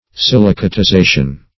Silicatization \Sil`i*ca*ti*za"tion\, n.